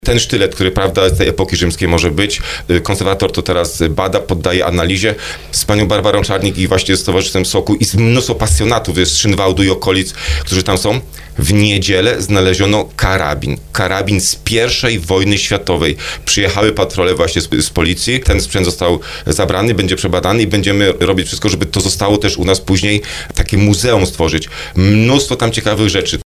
Jak mówi wójt gminy Skrzyszów Marcin Kiwior są przypuszczenia, że to może być sztylet rzymski.